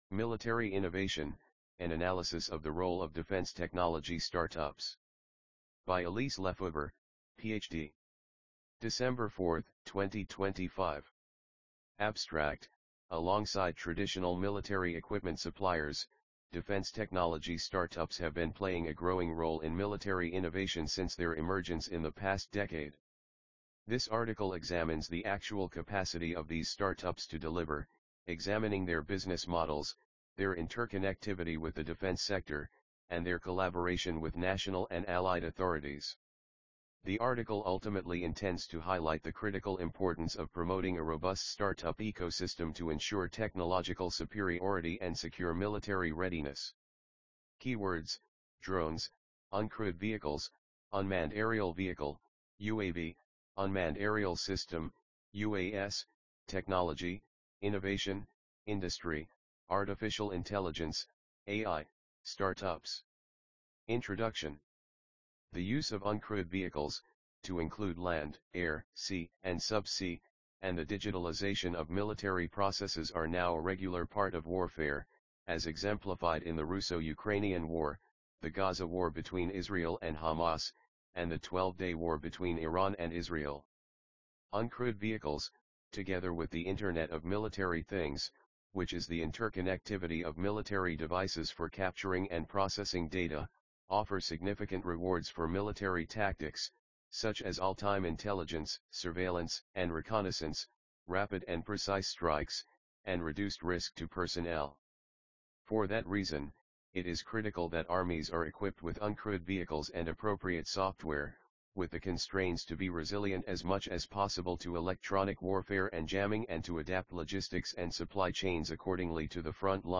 EXP_Military Innovation_AUDIOBOOK.mp3